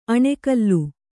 ♪ aṇe kallu